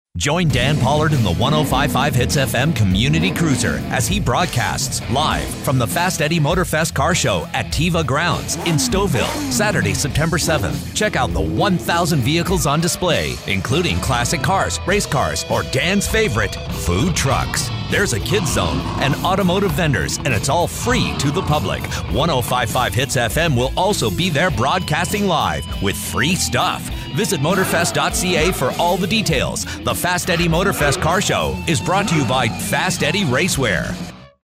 Fast Eddie Motorfest 105.5 Hits FM Spot